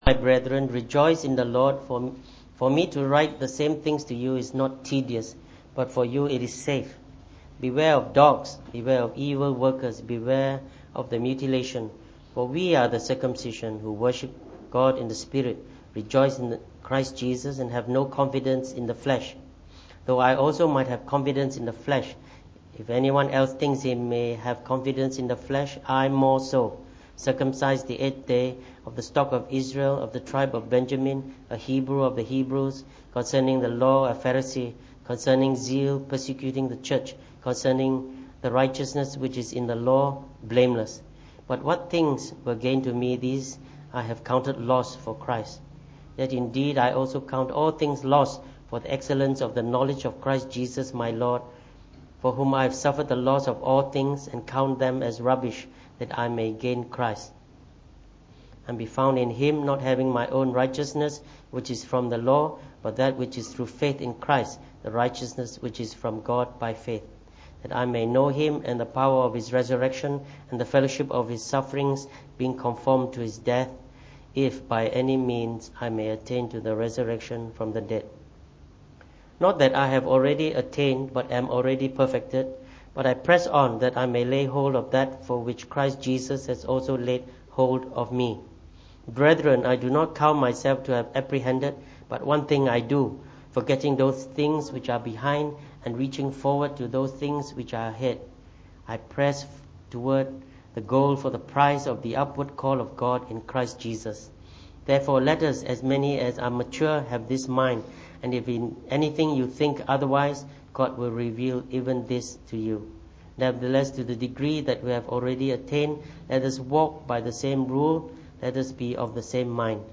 Preached on the 12th of March 2017.